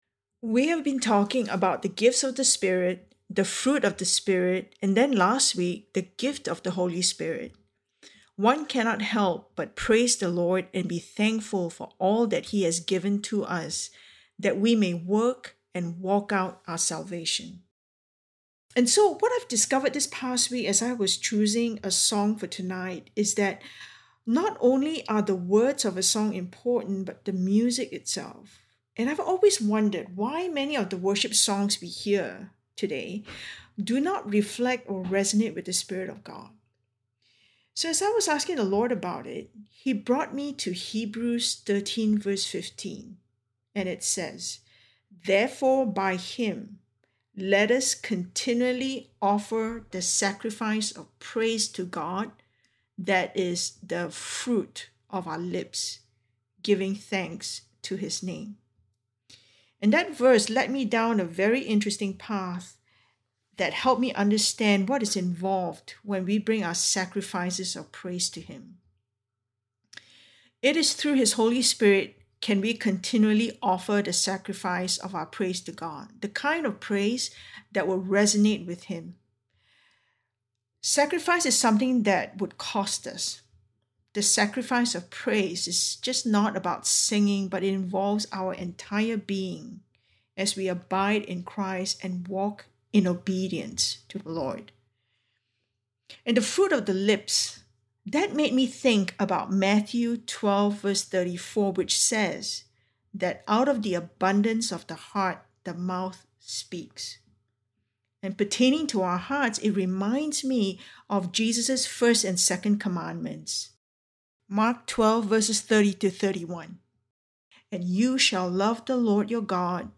A message from the series "Spiritual Body Building."